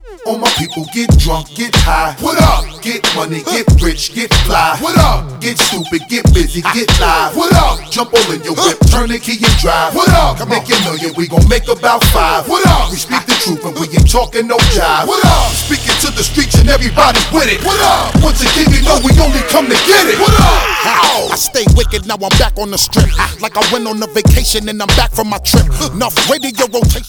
• Качество: 128, Stereo
громкие
Rap
Gangsta rap